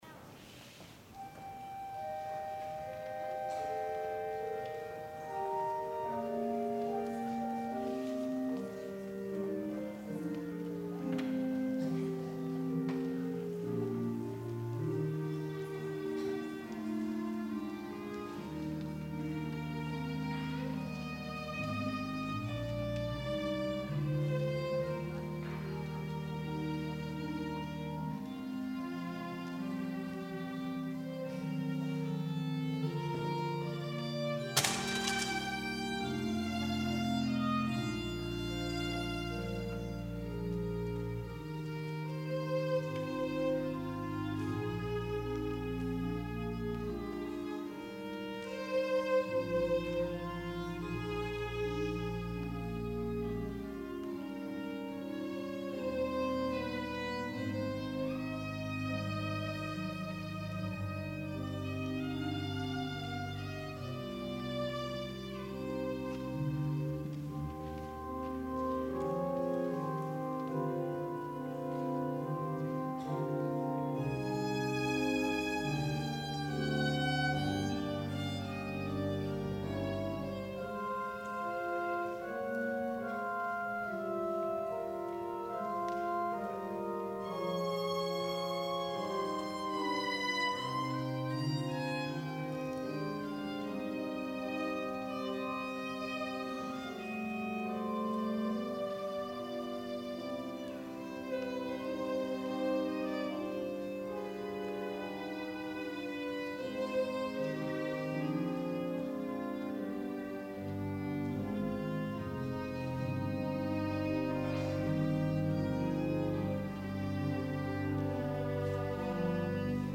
violin
organ